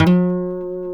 G 3 HAMRNYL.wav